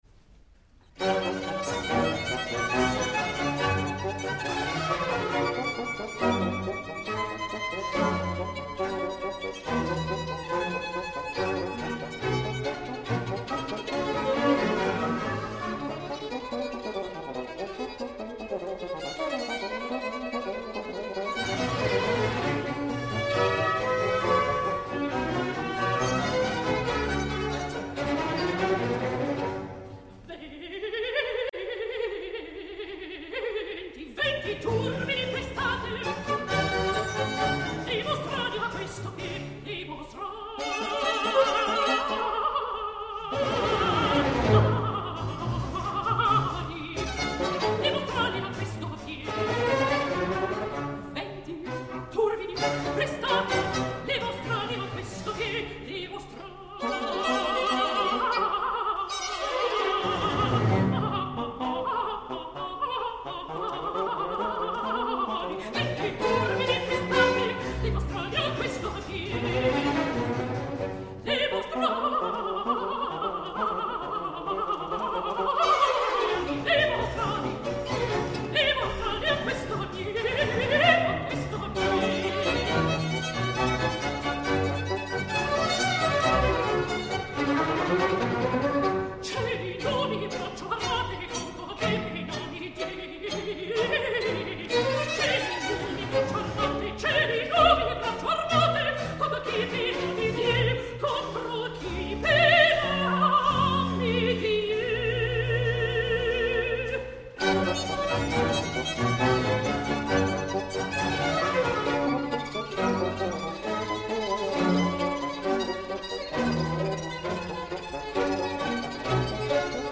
Doncs si, tinc “DiDonatitis”, que és ni més ni menys un estat d’admiració cap a la mezzo americana que aquests dies està fent aixecar el públic del Liceu, després de cantar una Angelina de La Cenerentola absolutament fascinant.
Us deixo dos trossets d’un concert que va fer el passat 30 de novembre (el mateix dia que el Liceu és rendia davant de Juan Diego Flórez) al Théâtre des Champs-Elisées de Paris amb l’Ensemble Matheus dirigit per Jean-Christophe Spinosi.